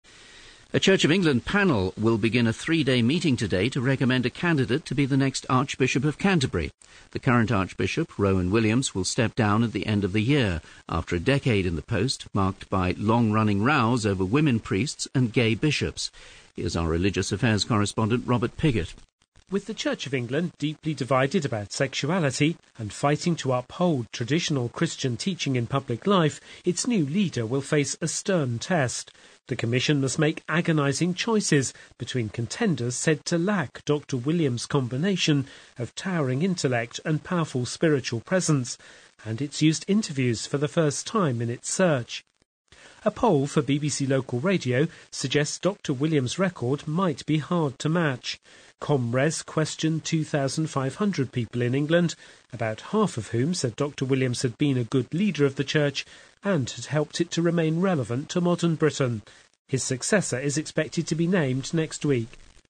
Radio voicer example